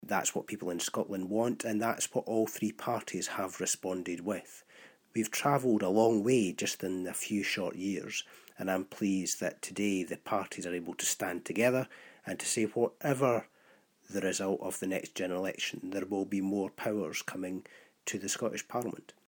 Scottish LibDem leader and Fife MSP Willie Rennie says they're not merging their proposals, but highlighting the intention to go much further on fiscal powers and social security: